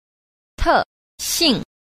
3. 特性 – tèxìng – đặc tính